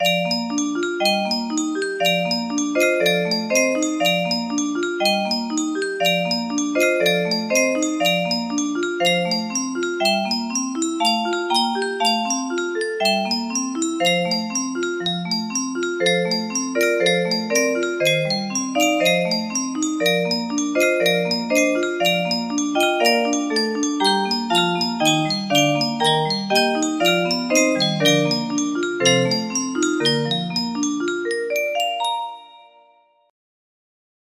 O du frohliche music box melody
Full range 60